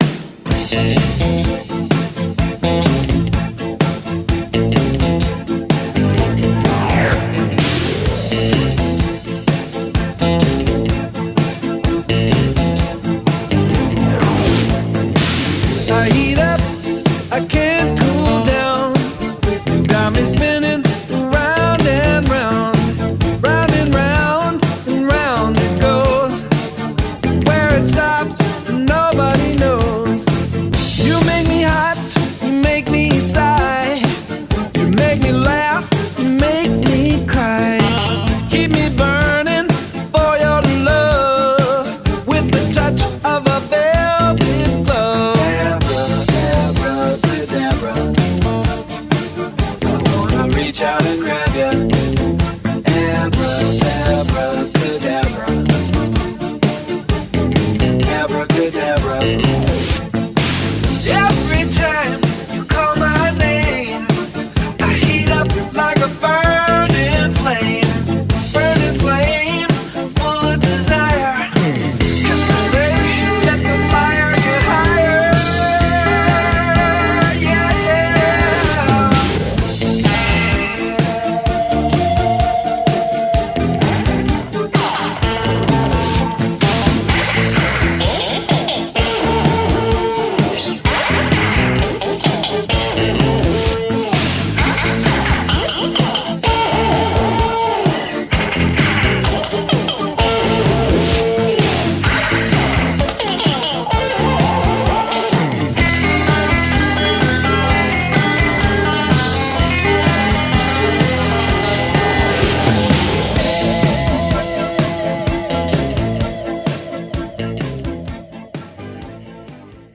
BPM120-139
Audio QualityMusic Cut